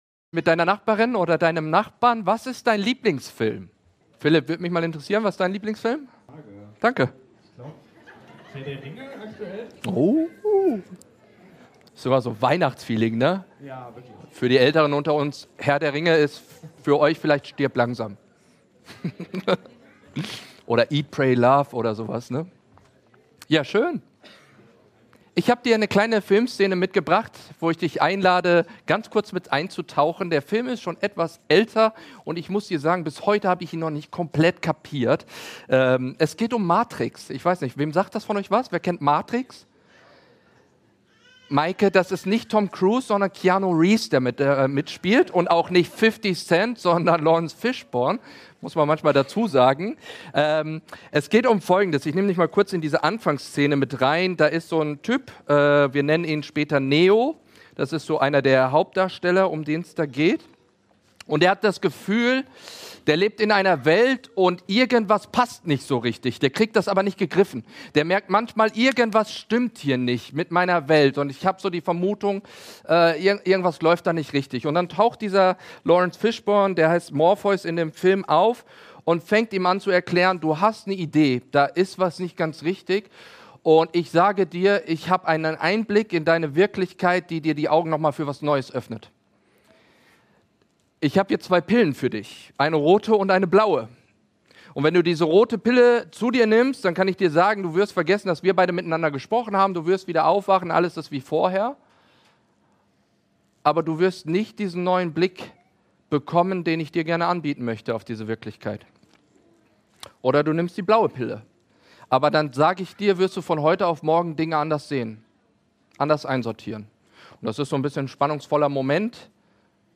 Gottes Weg vorbereiten ~ Predigt-Podcast von unterwegs FeG Mönchengladbach Podcast